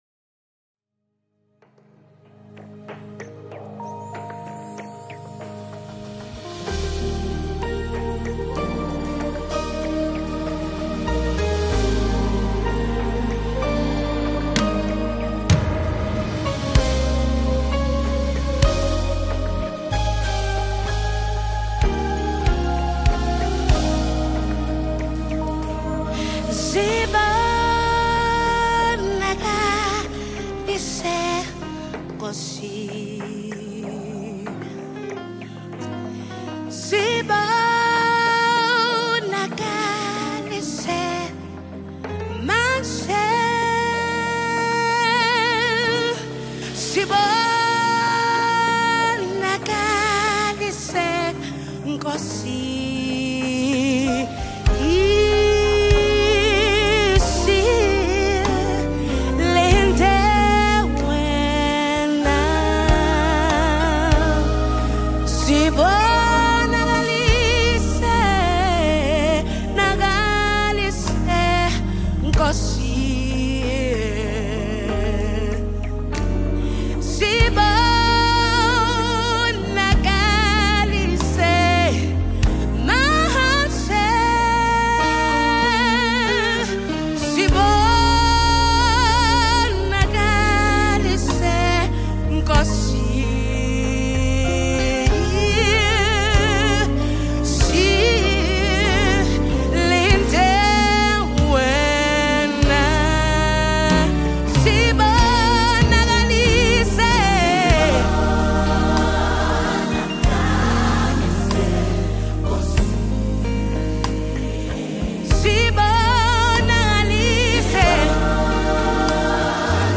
Home » Gospel
It delivers a sound that stays consistent throughout.